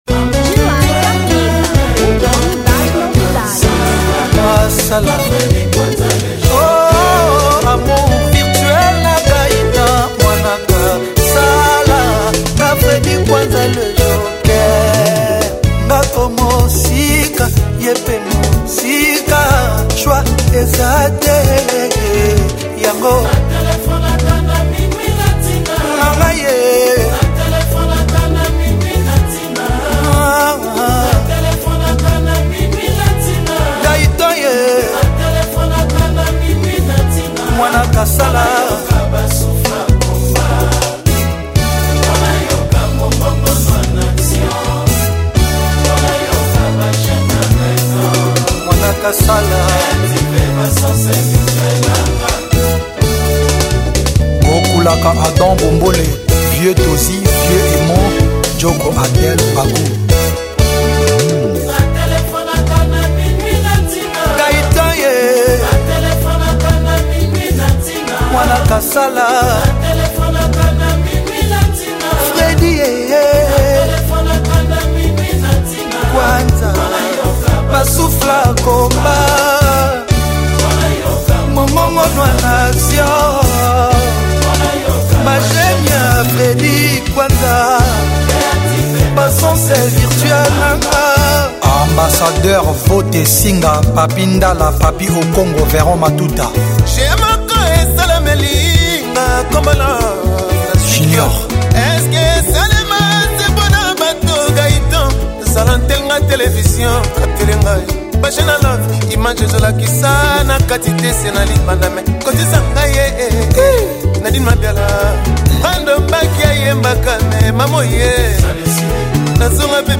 Rumba